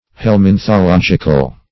Search Result for " helminthological" : The Collaborative International Dictionary of English v.0.48: Helminthologic \Hel*min`tho*log"ic\, Helminthological \Hel*min`tho*log"ic*al\, a. [Cf. F. helminthologique.] Of or pertaining to helminthology.